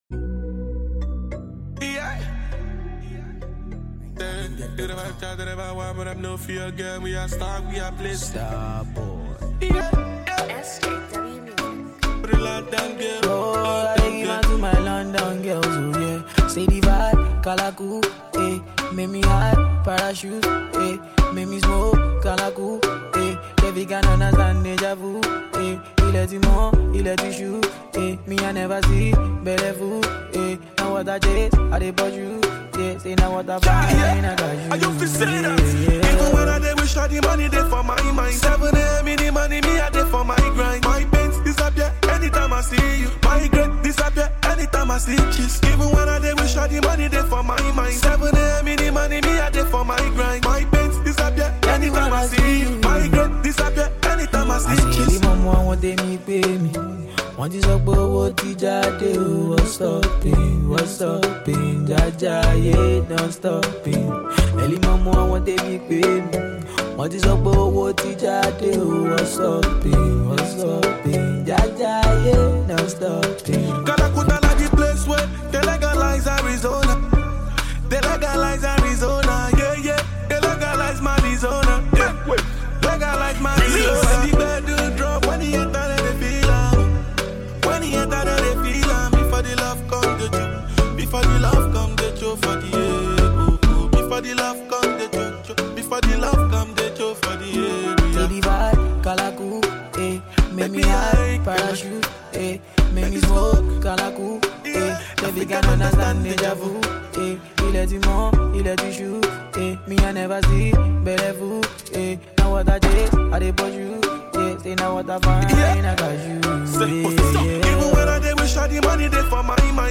harmonious track